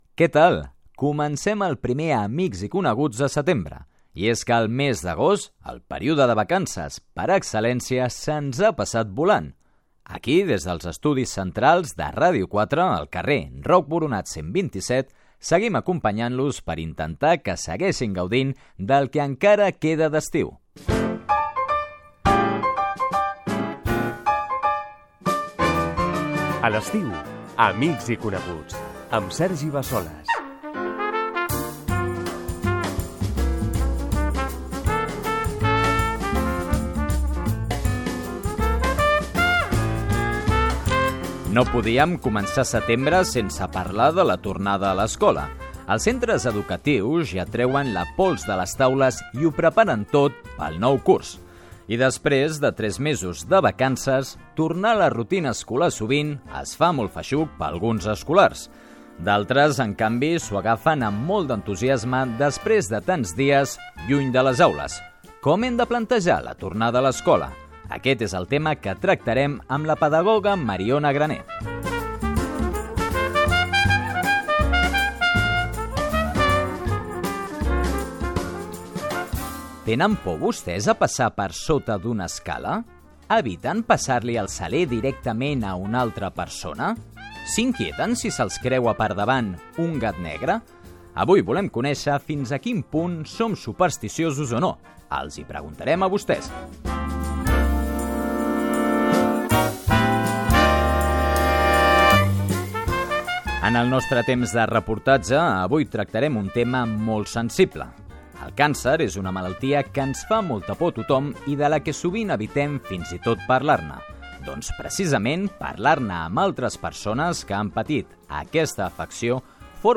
Presentació, careta del programa, sumari de continguts, tema musical